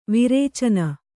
♪ viracane